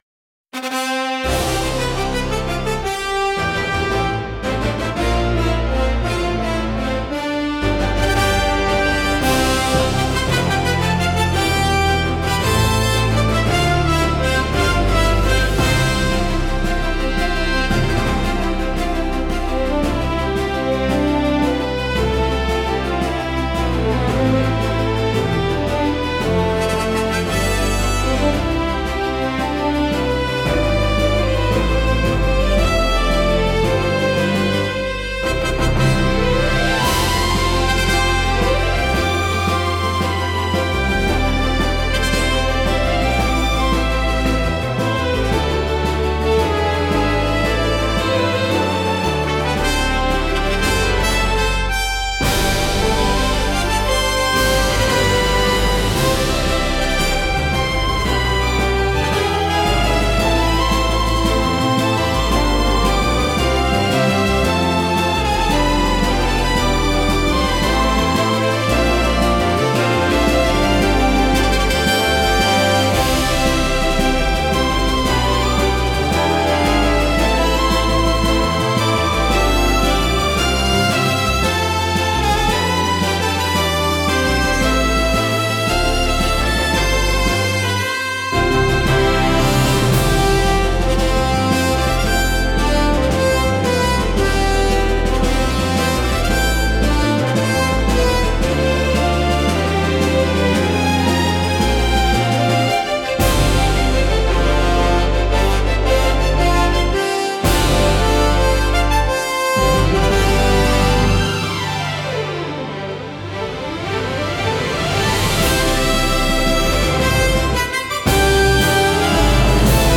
高揚感と喜びを強調し、場の雰囲気を盛り上げる役割を果たします。華やかで勢い のあるジャンルです。